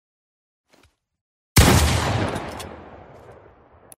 Pump Shoty Fornite